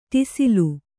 ♪ ṭisilu